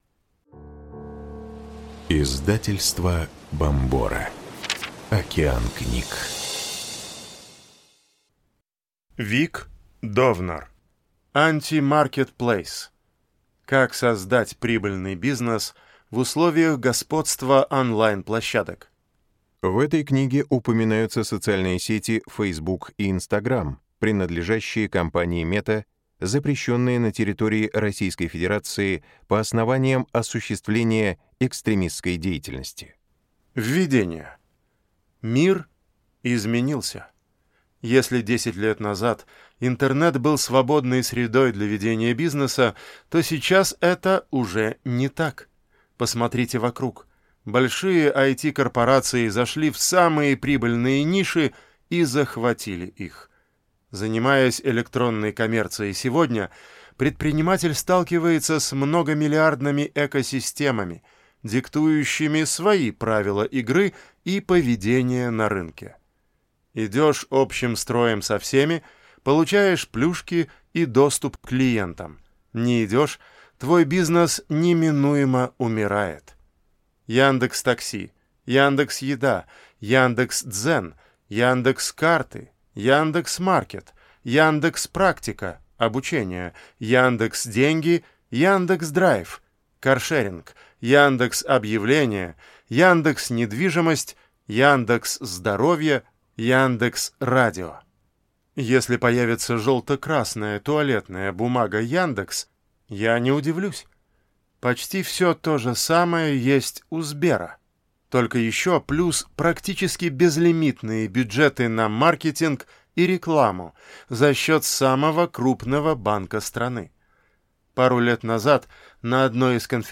Аудиокнига Антимаркетплейс. Как создать прибыльный бизнес в условиях господства онлайн-площадок | Библиотека аудиокниг